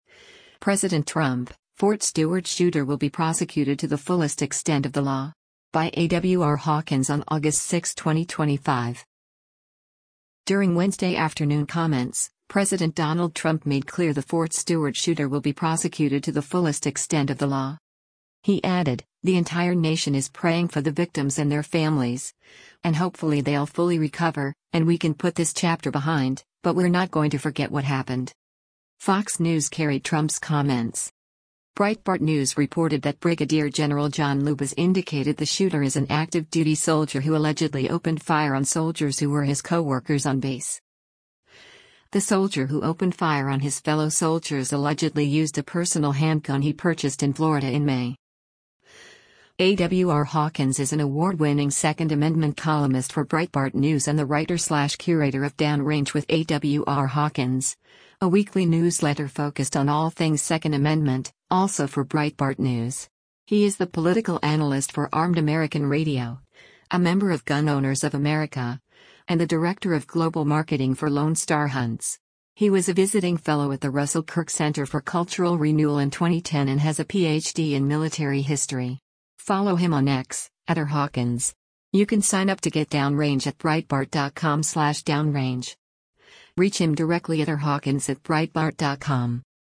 U.S. President Donald Trump speaks to the media as he departs the White House on August 01
During Wednesday afternoon comments, President Donald Trump made clear the Fort Stewart shooter “will be prosecuted to the fullest extent of the law.”